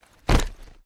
Бронированный жилет накинули поверх себя